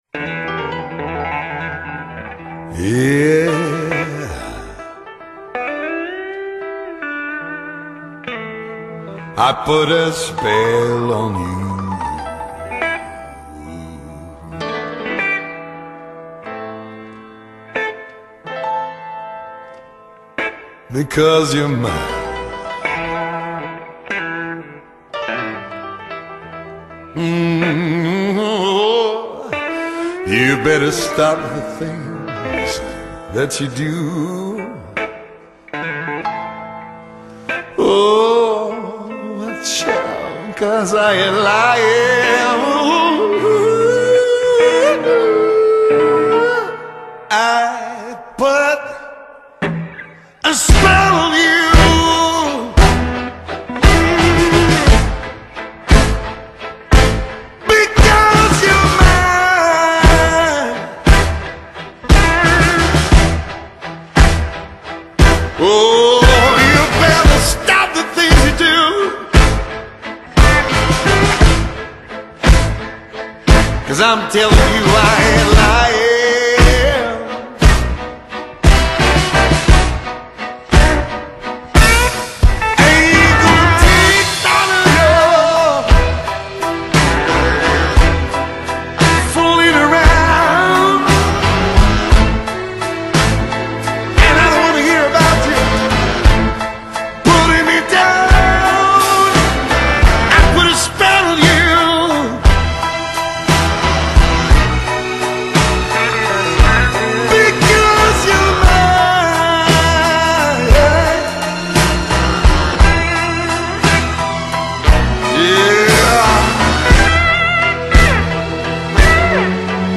Blues And Jazz Para Ouvir: Clik na Musica.